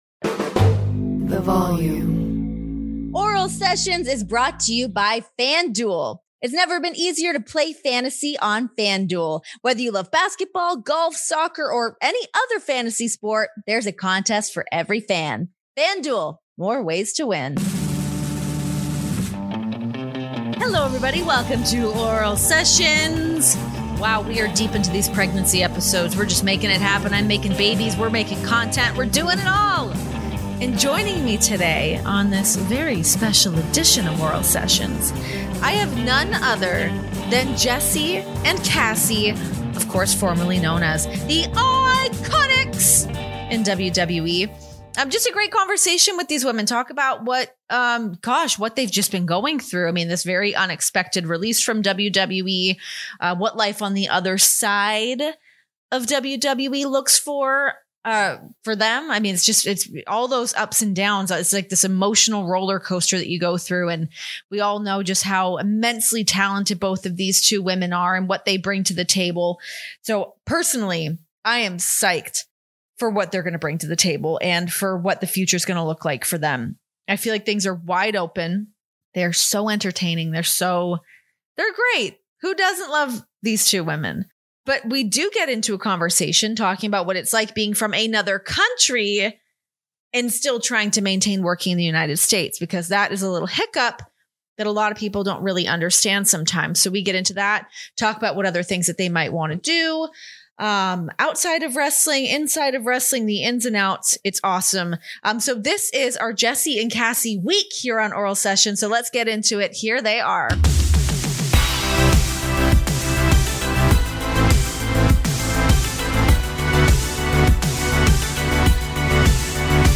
They may not have the name anymore, but Jessica McKay and Cassie Lee are every bit as iconic as you remember. The former WWE Women's Tag Team Champions discuss the blessing and curse of their shared release, Jess' ice-queen American accent and what exactly "Off Her Chops" means in an emotional Oral Sessions interview.